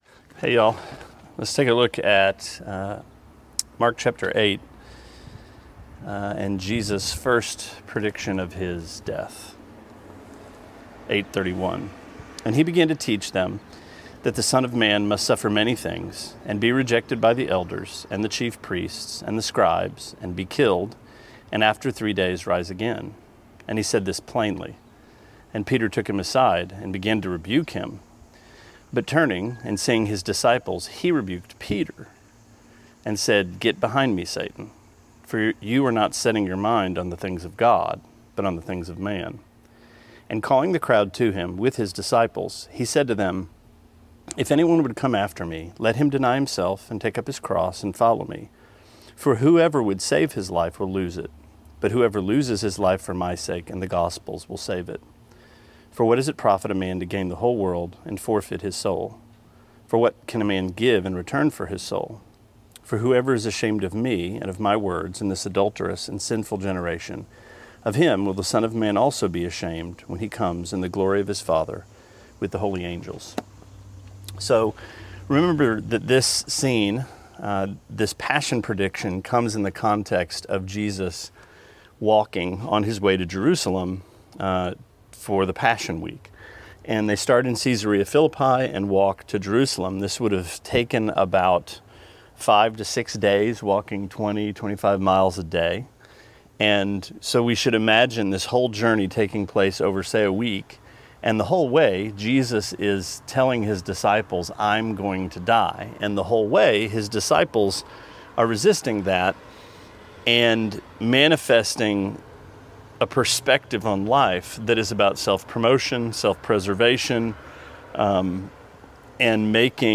Sermonette 7/16: Mark 8:31-38: What Kind of King?